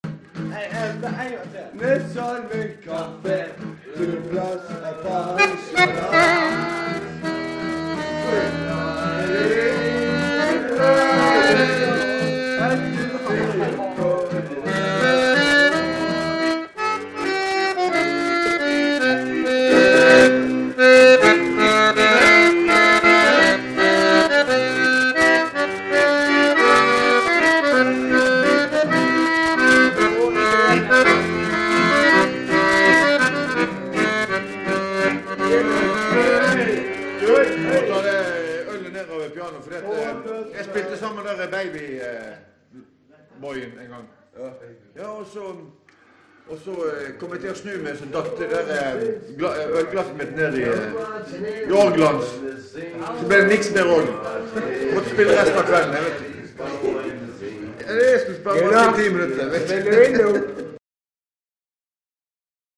Her har vi et amatøropptak
Varierende og allsidig fest og danse -musikk.